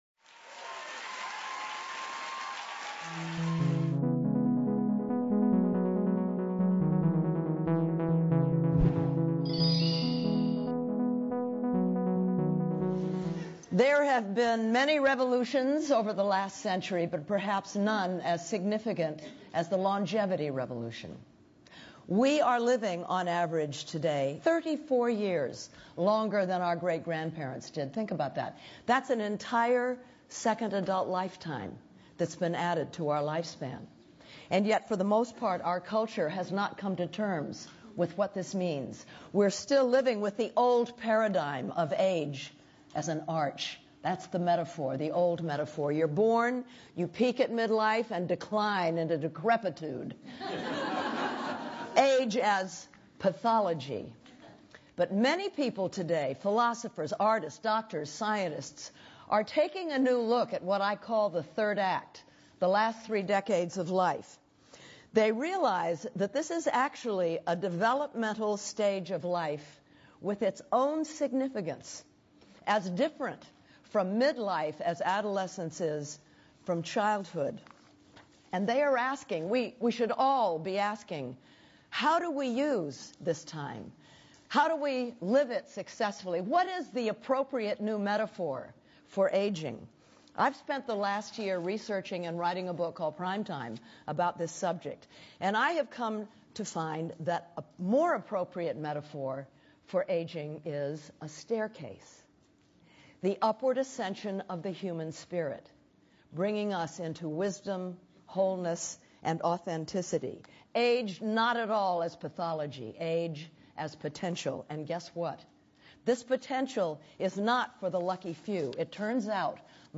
Following is the full transcript of American actress Jane Fonda’s TEDx Talk titled “Life’s Third Act” at TEDxWomen.